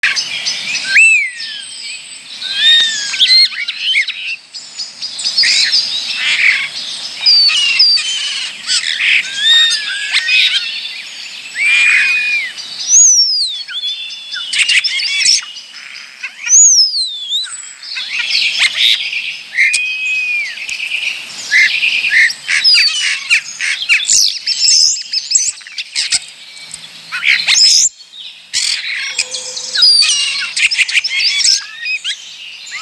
Graurul (Sturnus vulgaris)
Este cea mai întâlnită pasăre din Pădurea Crăiască. Cântă… dar și imită! Poate copia alte păsări, ba chiar și telefoane sau sonerii.
Ascultă-l cum cântă!
Graur.m4a